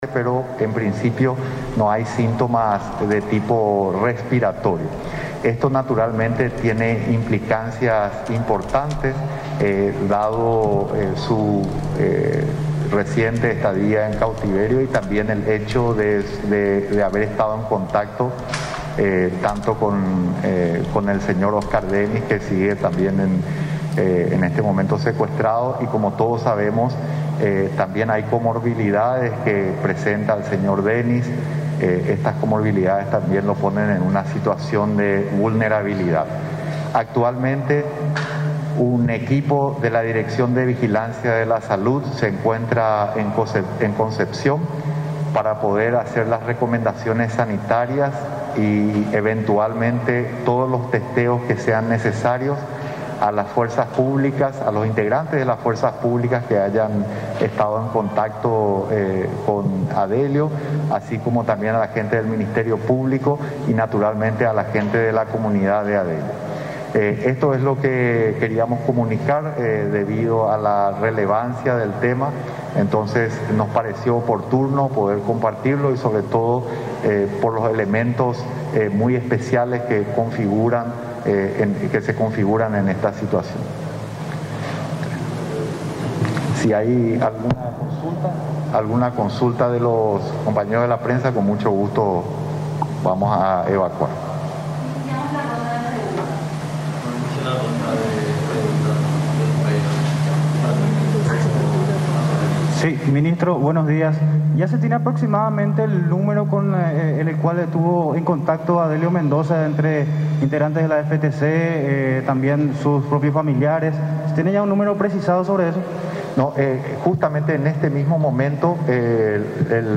18-CONFERENNCIA.mp3